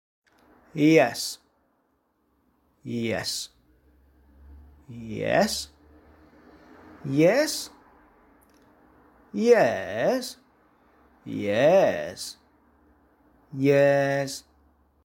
例如「yes」一字，可用7種語調讀出，語氣不同，意思也不盡相同。
1. high fall：感興趣
2. low fall：權威／沒趣
3. low rise：疑惑／等待回應
4. high rise：質疑
5. fall rise：未完成
6. rise fall：理所當然／諷刺
7. mid-level：未完成／緩和氣氛
yes_7_nuclear_tones.mp3